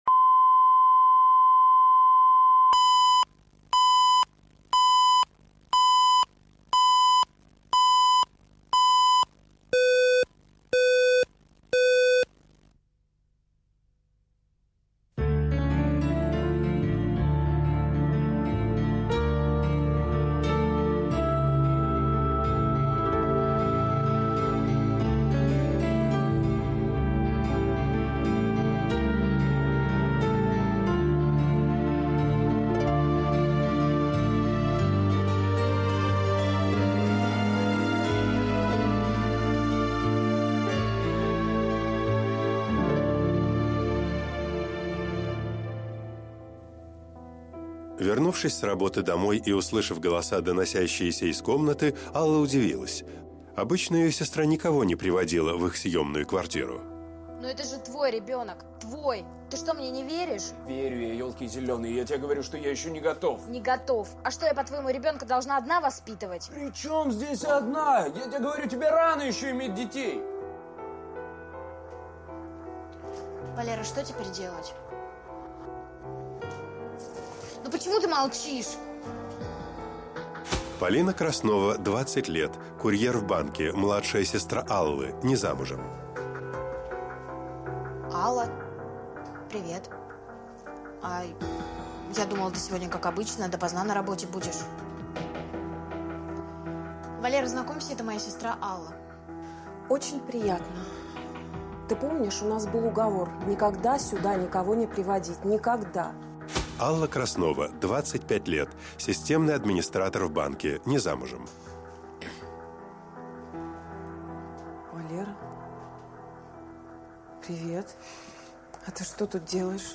Аудиокнига Жених сестры | Библиотека аудиокниг